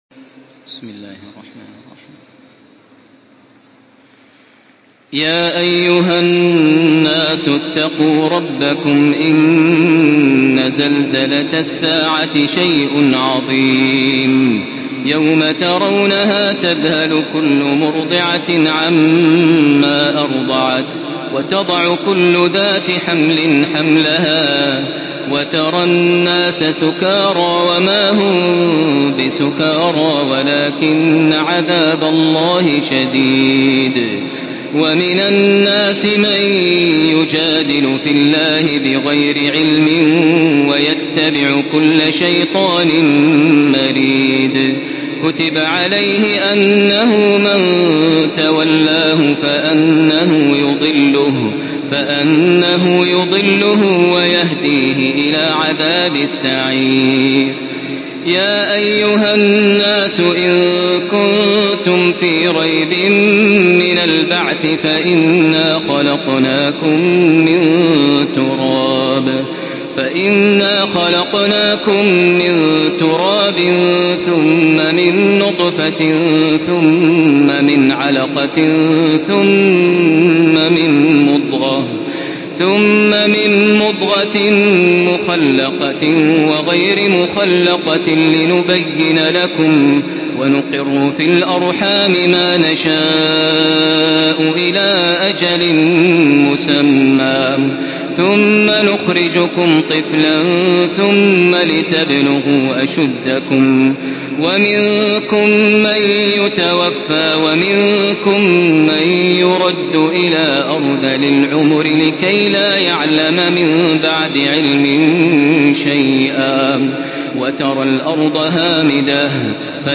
تحميل سورة الحج بصوت تراويح الحرم المكي 1435
مرتل حفص عن عاصم